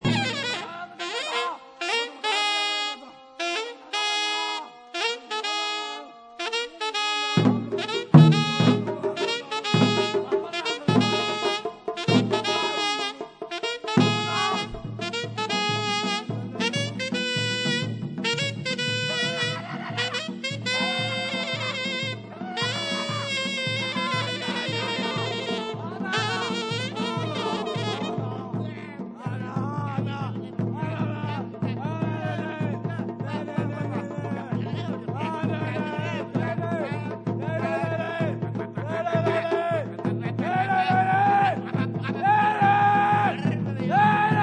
Ainsi des morceaux hurleurs -